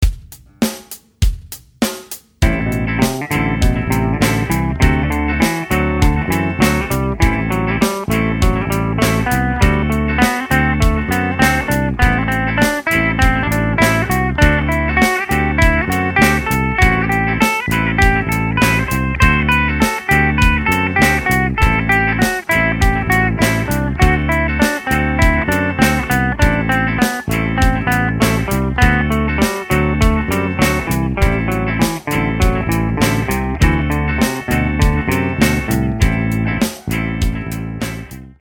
The final exercise is similar to the last except it plays 4 scale steps in a row.
dorian_a_4steps.mp3